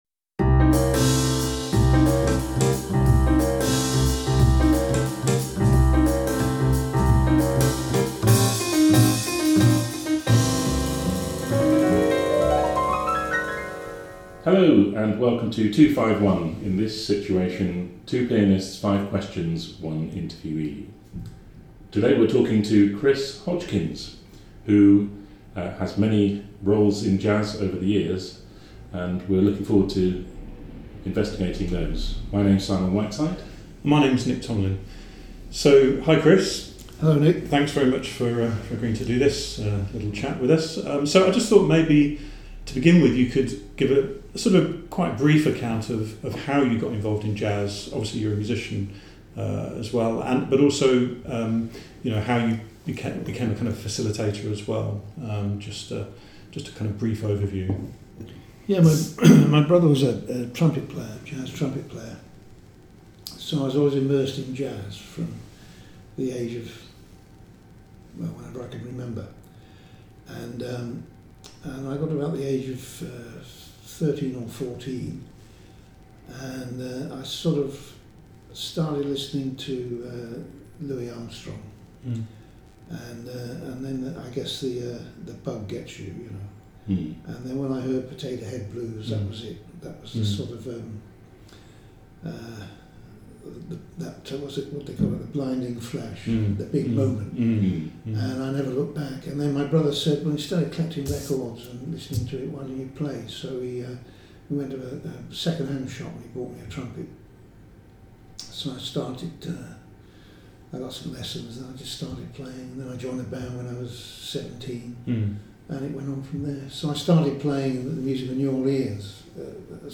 In this wide-ranging conversation